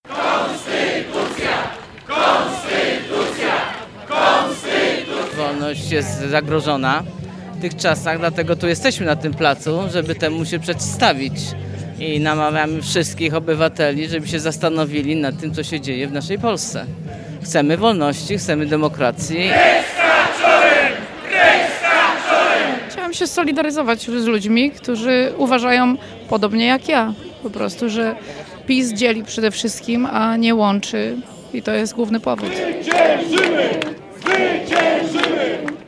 W niedzielne południe, w rocznicę częściowo wolnych wyborów z 4 czerwca 1989 roku, szczeciński Plac Solidarności wypełnił tłum z biało-czerwonymi i unijnymi flagami.
Wolność jest zagrożona, dlatego jesteśmy tu, żeby przeciwstawić się temu i namawiam wszystkich obywateli, żeby zastanowili się nad tym, co się dzieje w naszej Polsce. Chcemy wolności, chcemy demokracji (…) Chciałam się solidaryzować z ludźmi, którzy uważają podobnie jak ja, że PiS dzieli, a nie łączy i to jest główny powód mojego uczestnictwa – mówili uczestnicy manifestacji